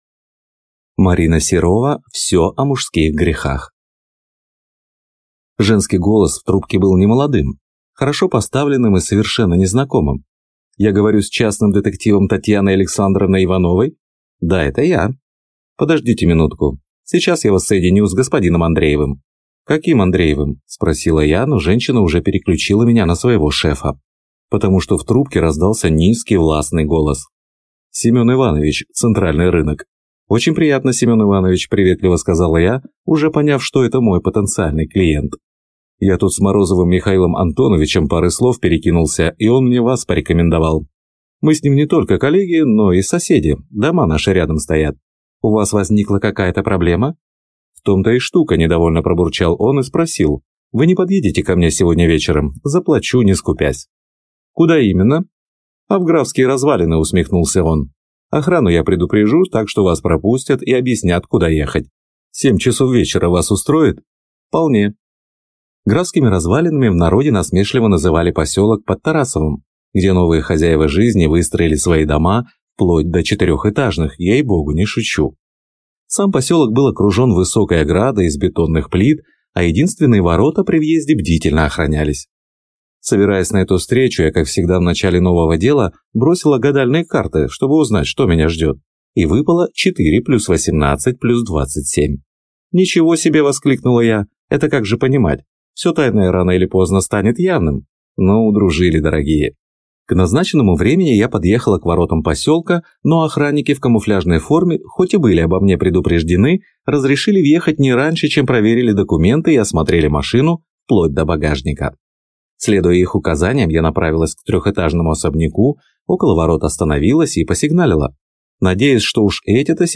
Аудиокнига Все о мужских грехах | Библиотека аудиокниг